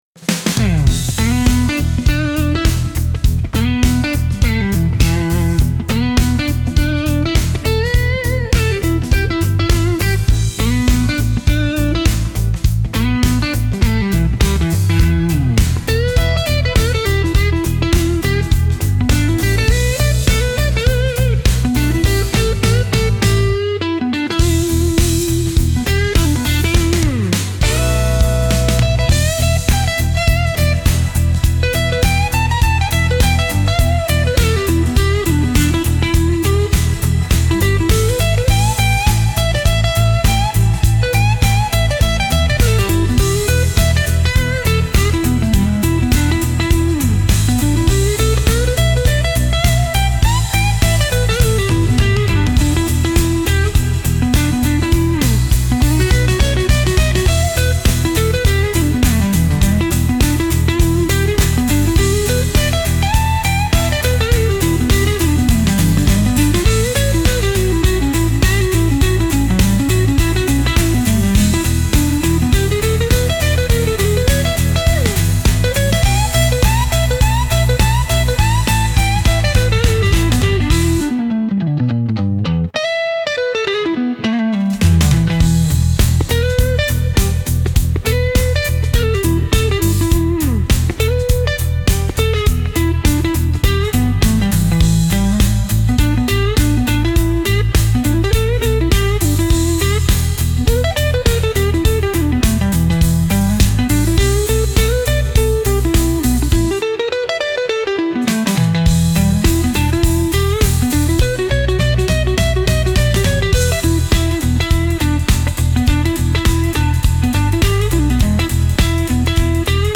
Instrumental - RLMradio Dot XYZ - 2.56 .mp3